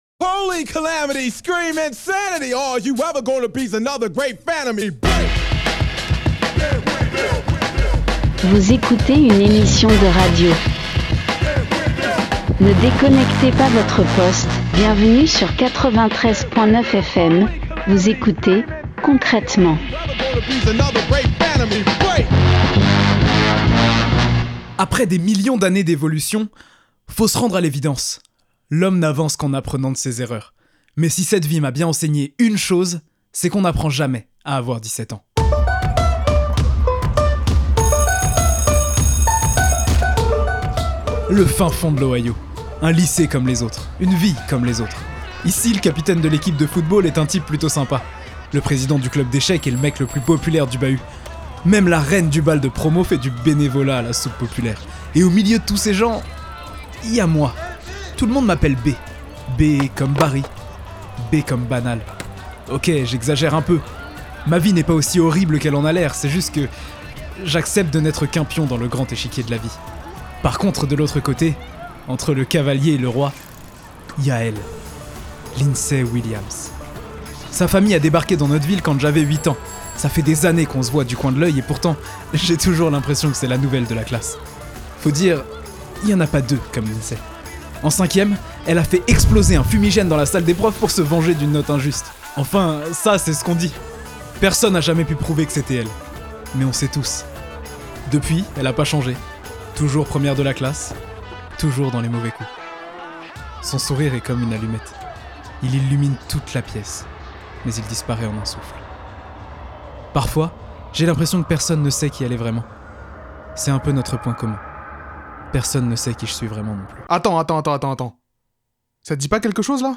Partager Type Création sonore Société lundi 8 avril 2024 Lire Pause Télécharger Qui trace les règles de la nostalgie ?